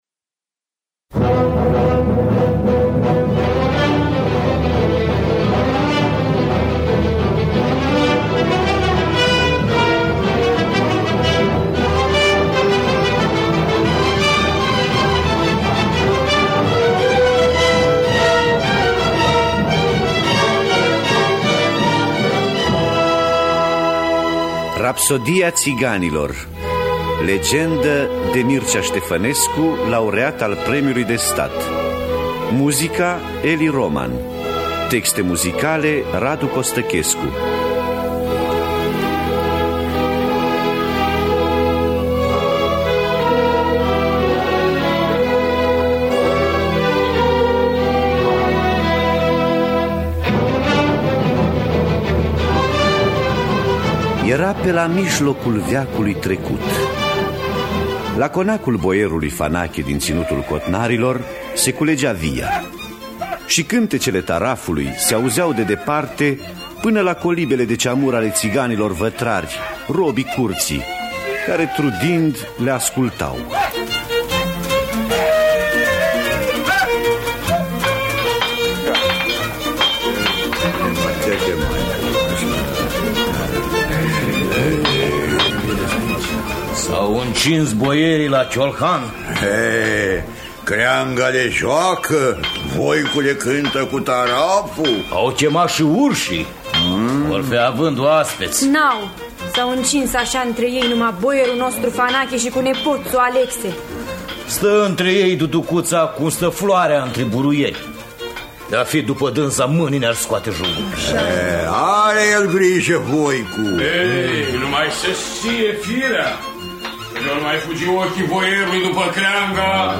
Rapsodia țiganilor de Mircea Ștefănescu – Teatru Radiofonic Online